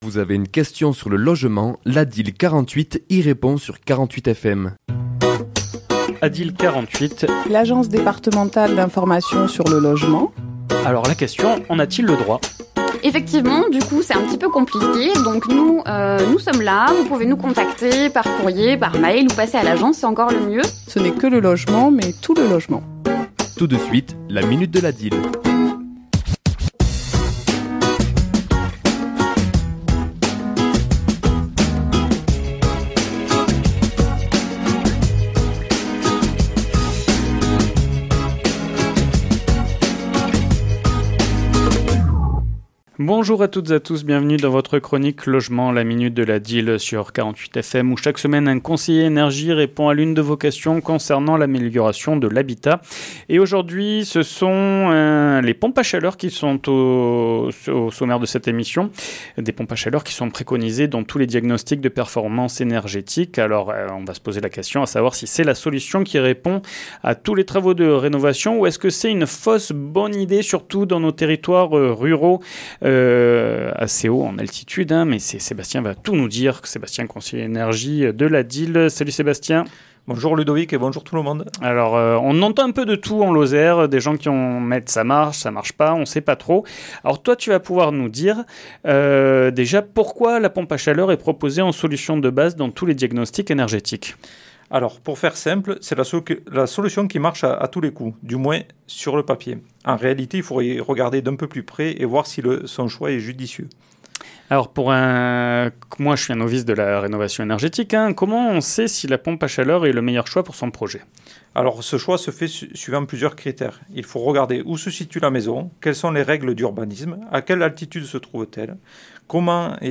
Chronique diffusée le mardi 17 juin à 11h et 17h10